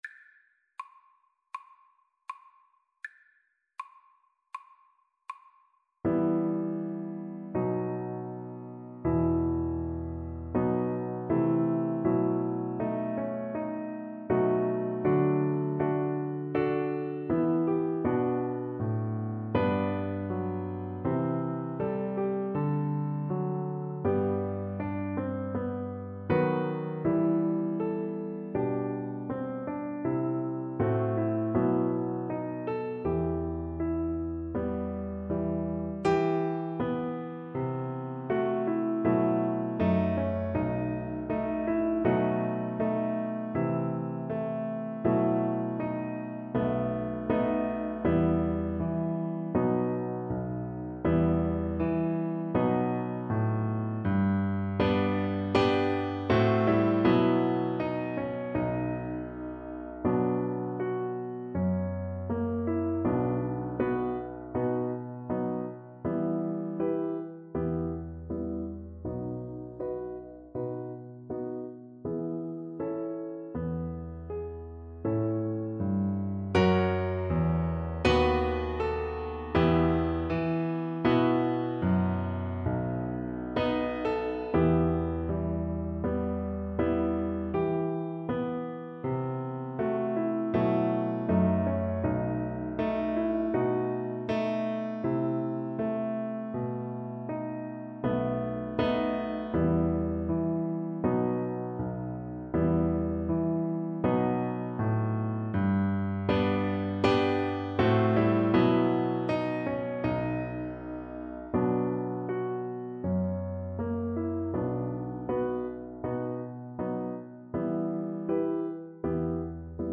Cello version
= 80 Moderato affettuoso
4/4 (View more 4/4 Music)
Classical (View more Classical Cello Music)